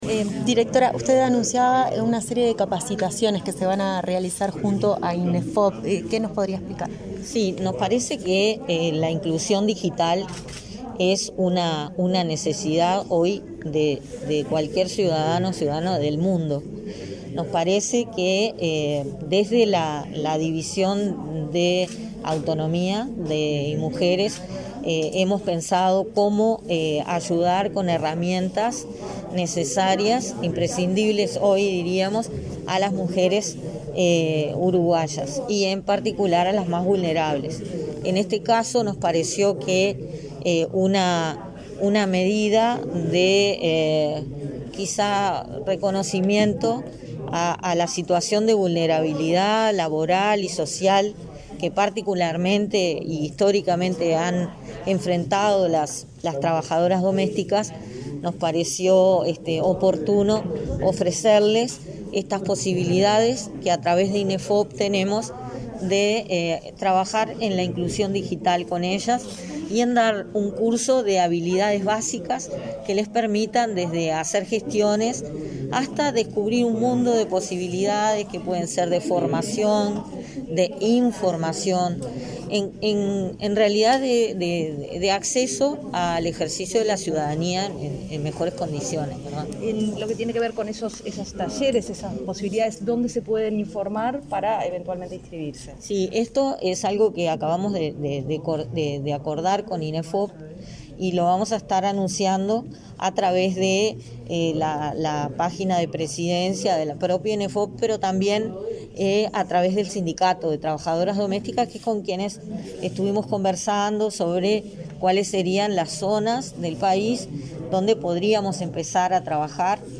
Declaraciones a la prensa de la directora del Banco de Previsión Social, Daniela Barindelli
Declaraciones a la prensa de la directora del Banco de Previsión Social, Daniela Barindelli 19/08/2021 Compartir Facebook X Copiar enlace WhatsApp LinkedIn Con motivo del Día de las Trabajadoras Domésticas, este 19 de agosto en la sede del Ministerio de Trabajo se realizó una conferencia de prensa. Al finalizar, la directora del Banco de Previsión Social realizó declaraciones a los medios de prensa.